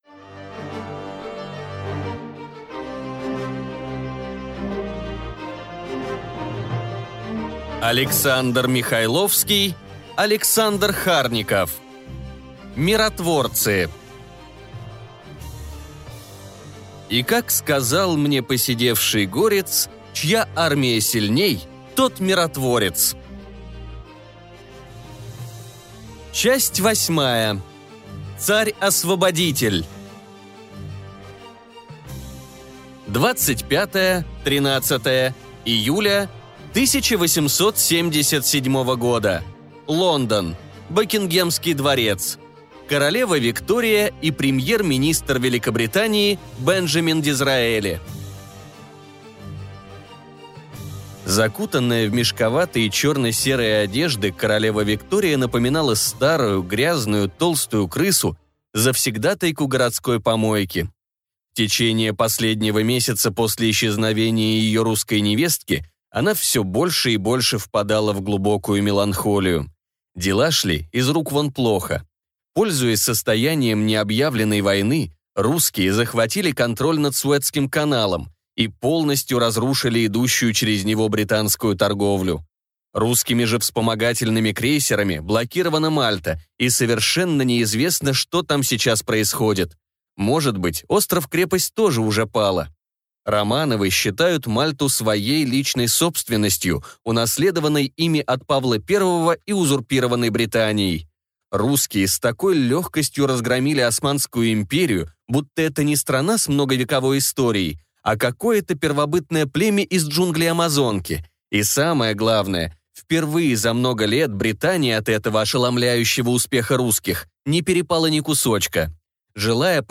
Aудиокнига Миротворцы